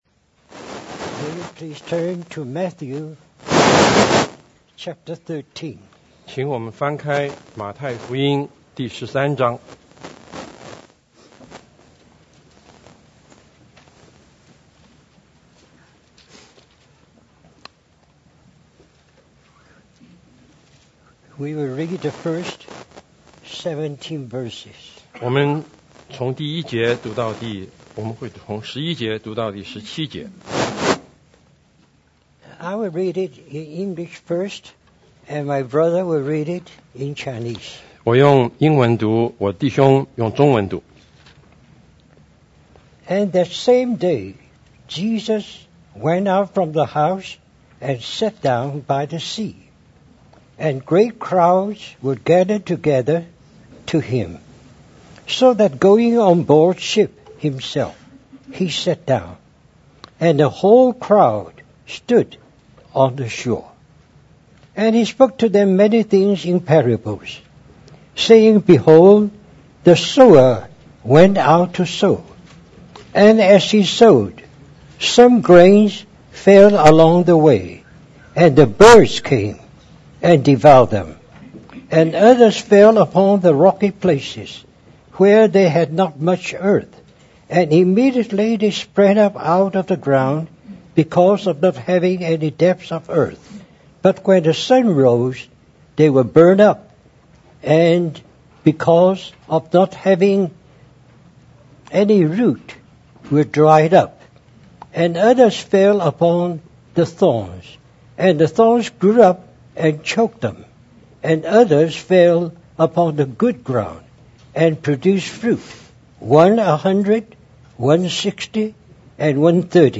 Our brother shares a series of four messages on The Gospel of the Kingdom